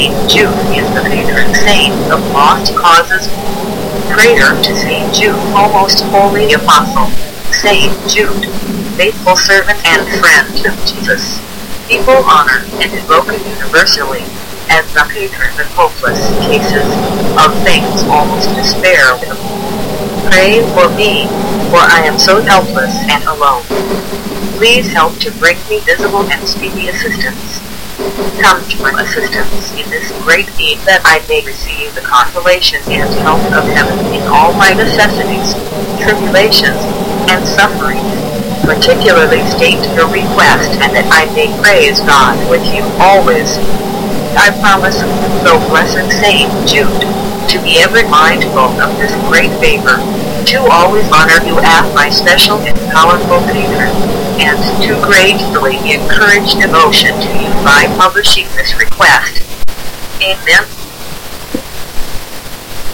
描述：这是一个电子声音，在雷声和大风的气氛中，用声音和电子管风琴的效果创造出的对圣裘德的祈祷